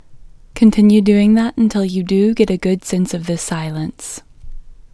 LOCATE OUT English Female 32